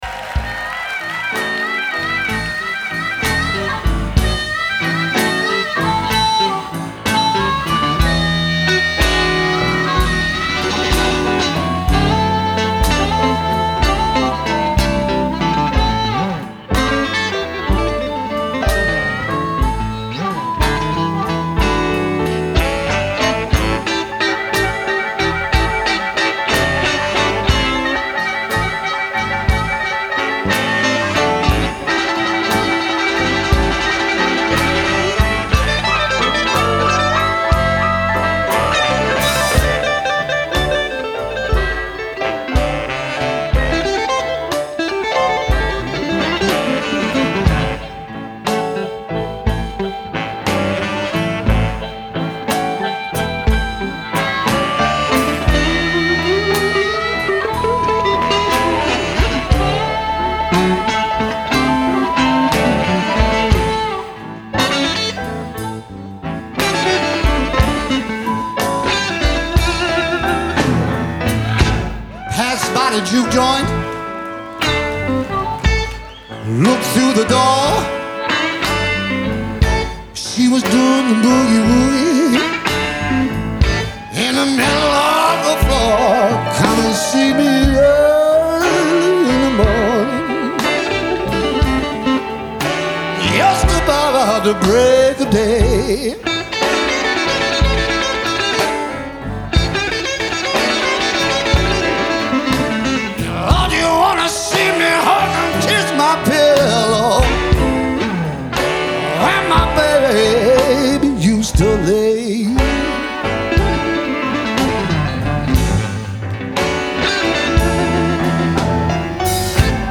Genre : Blues ,Rock
Live at the Fillmore, San Francisco, 1994